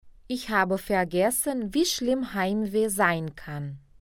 Dicas de pronúncia:
[h] som feito na garganta
[ei] som de ai
[w] som de v